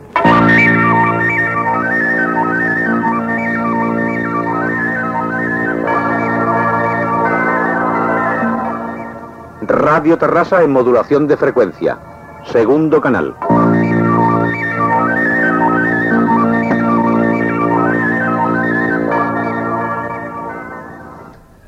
Identificació de l'emissora en FM.